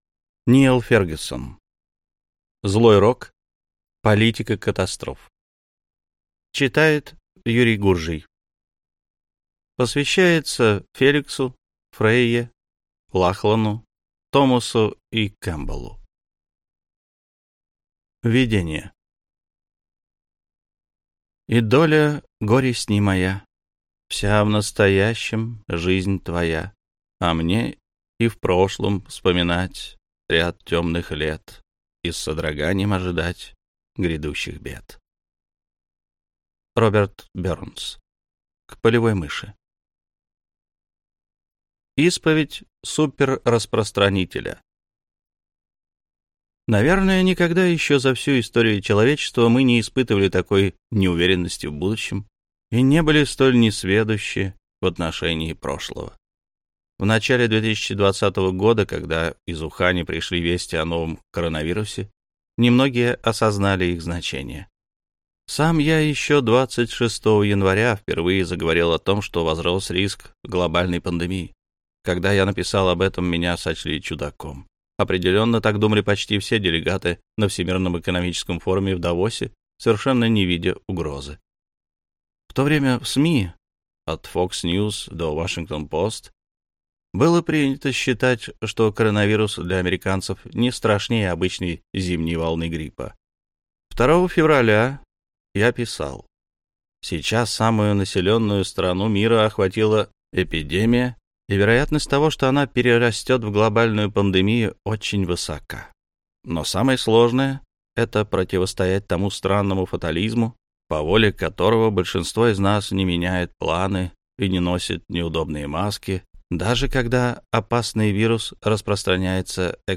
Аудиокнига Злой рок. Политика катастроф | Библиотека аудиокниг